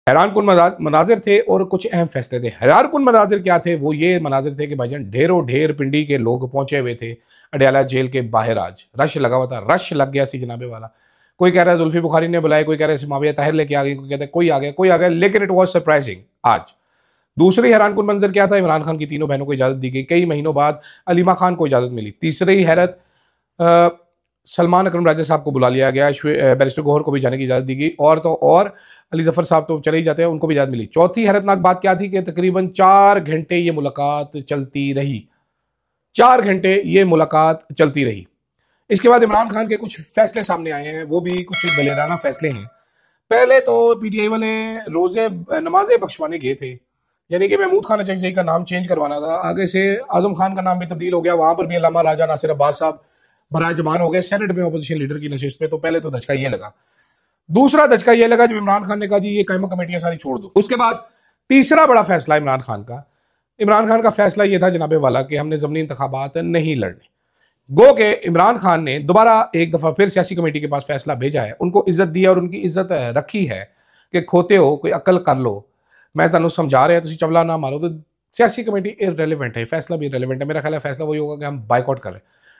Crowds Outside Adiala Jail | sound effects free download